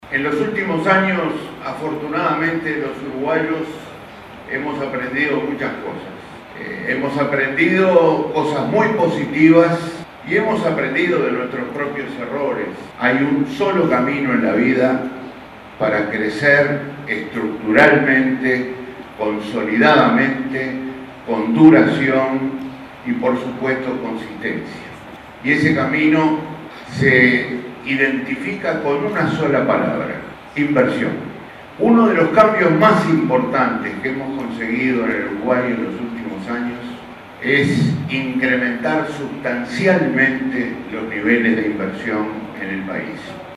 Al exponer ante el Consejo de China para la Promoción del Comercio Internacional, Astori afirmó: “En los últimos años, los uruguayos hemos aprendido que hay un solo camino para consolidar el crecimiento y se identifica con una sola palabra: inversión”.